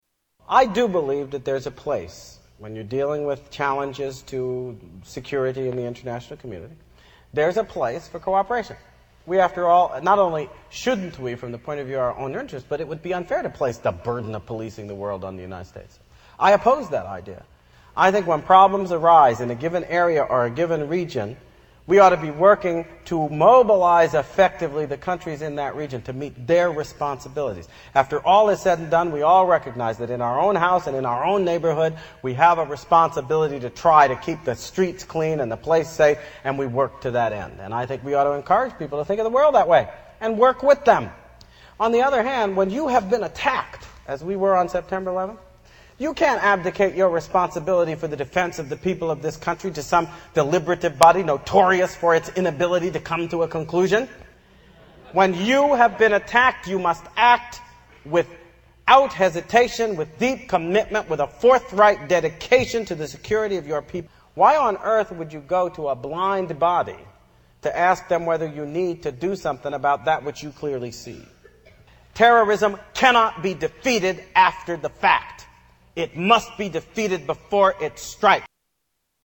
Tags: Political Alan Keyes audio Alan Keyes Alan Keyes Speeches The Tea Part